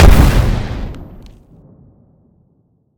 small-explosion-3.ogg